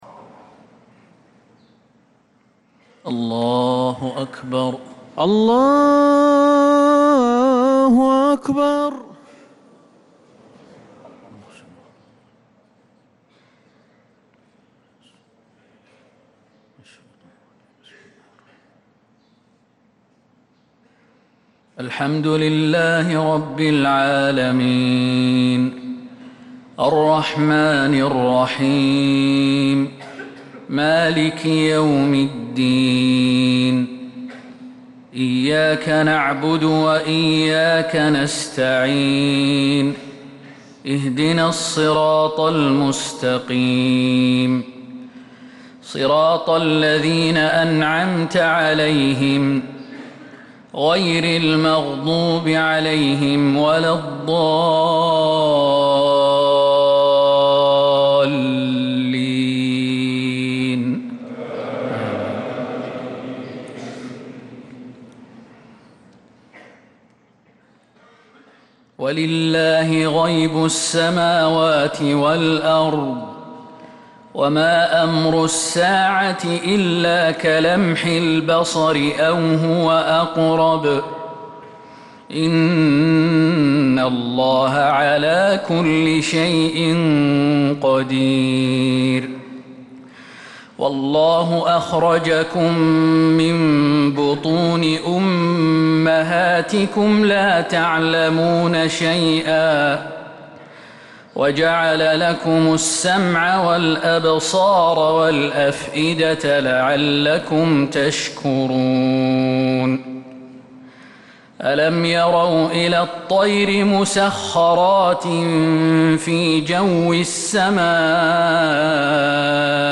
فجر الاثنين 12 محرم 1447هـ من سورة النحل 77-89 | Fajr prayer from Surat An-Nahl 7-7-2025 > 1447 🕌 > الفروض - تلاوات الحرمين